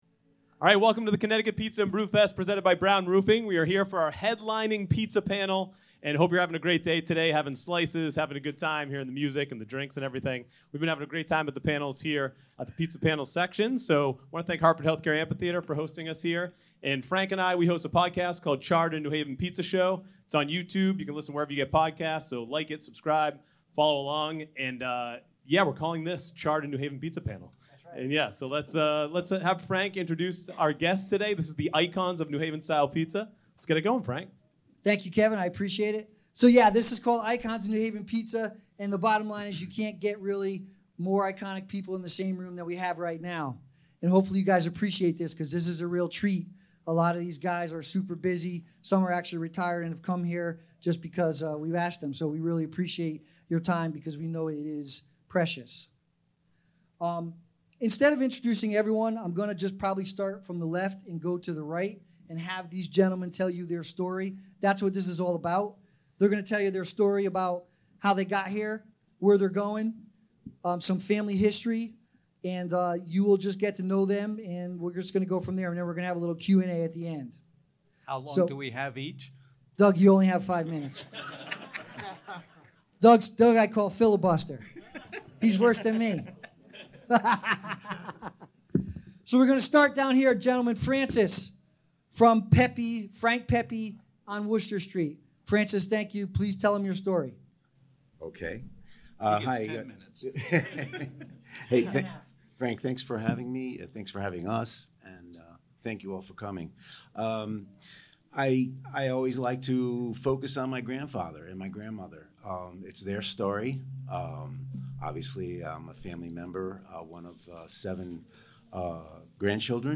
Re-live the main event panel at the 2024 Connecticut Pizza & Brew Fest